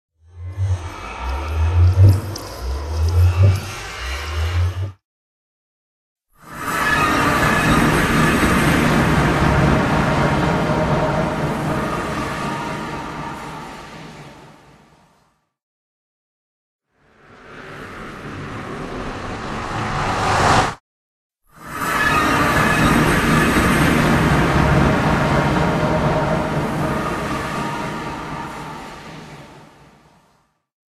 Портал в ад Minecraft